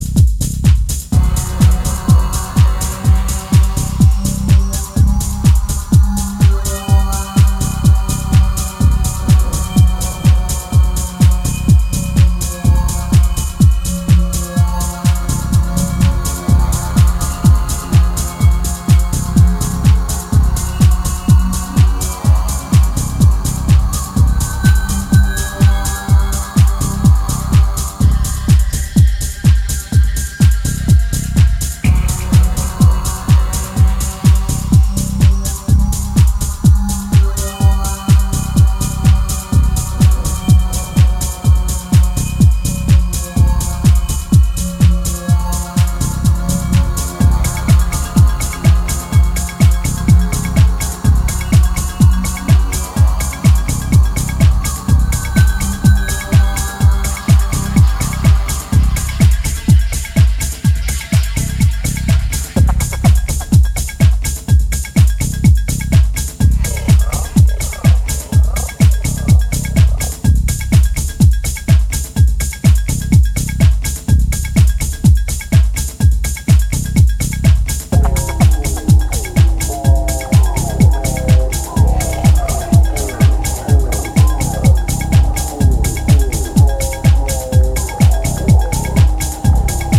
超ストンピンなキックと捻れたアシッドでフロアを浮かせる
全体的に意識されていそうなミニマルな展開が引力を高めています。